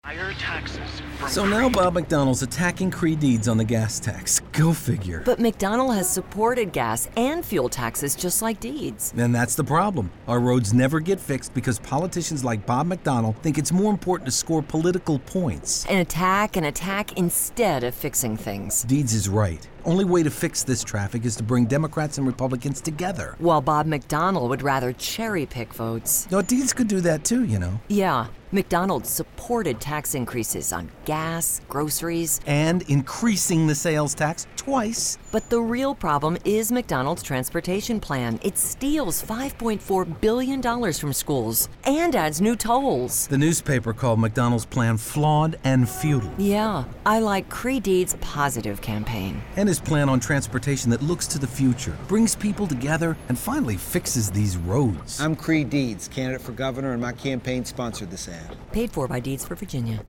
Hampton Roads and Richmond get a new radio ad called “Breaks.”